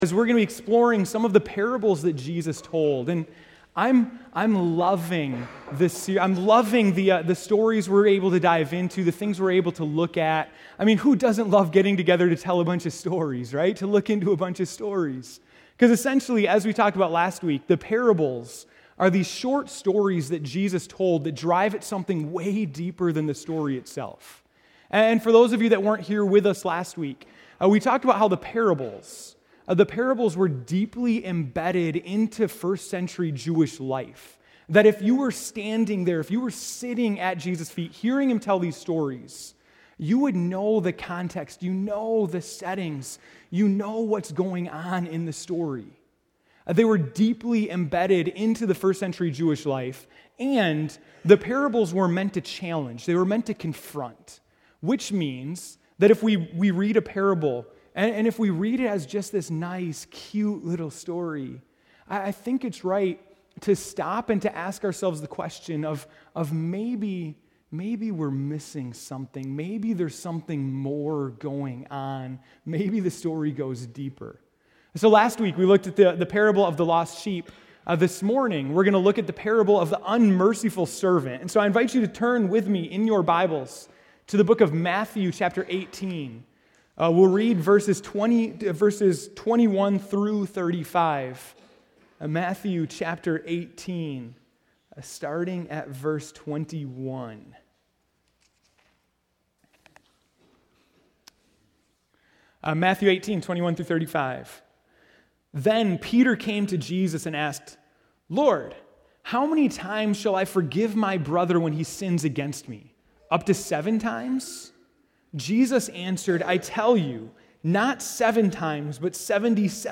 January 11, 2015 (Morning Worship)